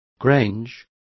Complete with pronunciation of the translation of grange.